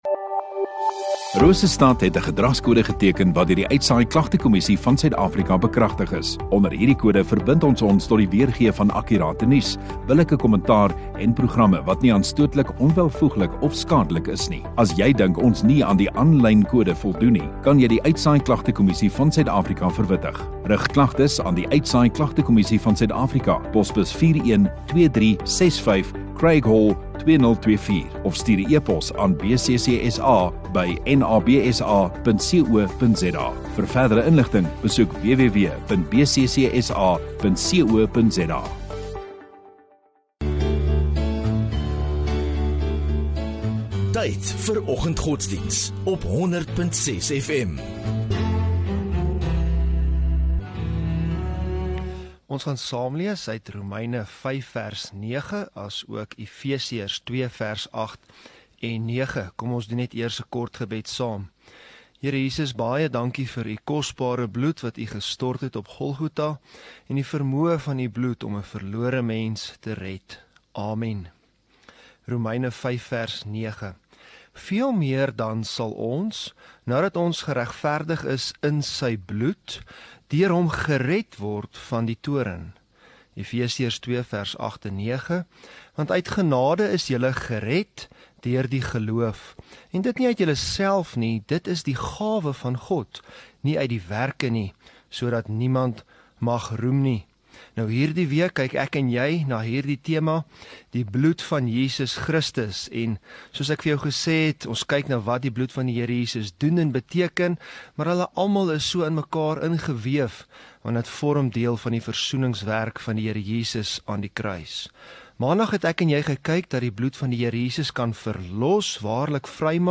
1 Oct Woensdag Oggenddiens